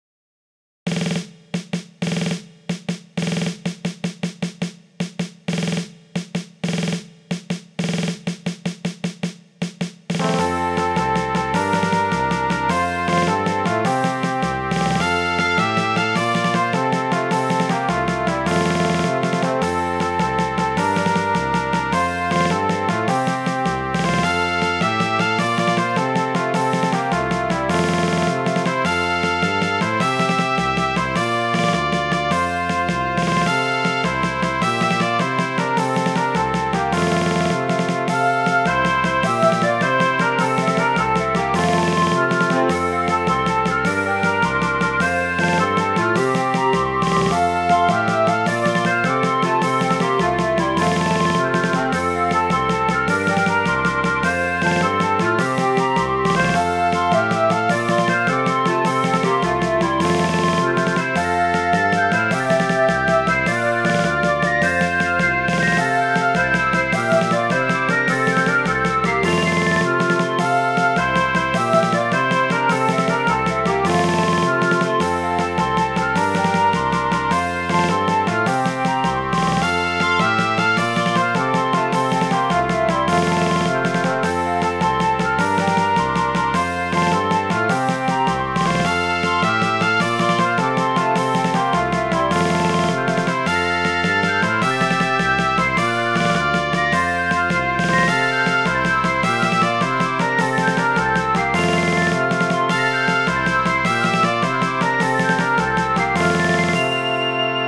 Midi File, Lyrics and Information to Bonnie Blue Flag